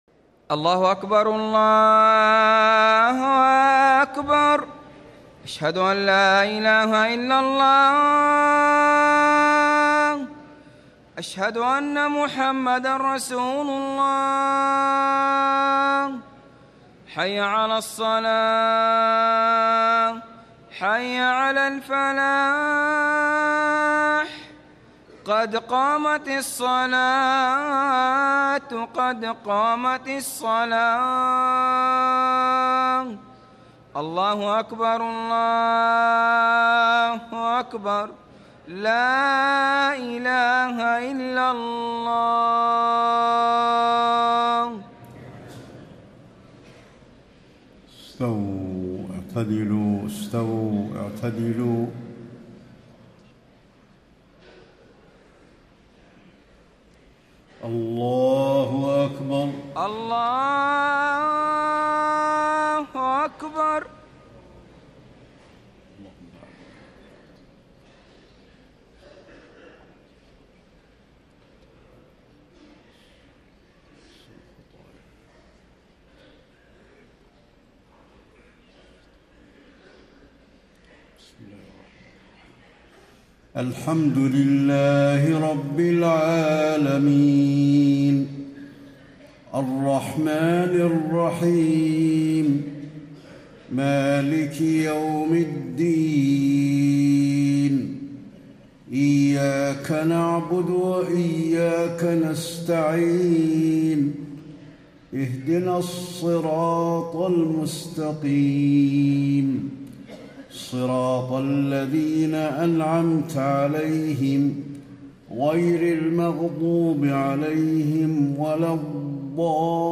صلاة المغرب 6 - 1 - 1435هـ سورتي الطارق و التين > 1435 🕌 > الفروض - تلاوات الحرمين